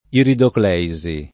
[ iridokl $ i @ i ]